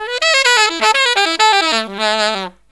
63SAXMD 12-R.wav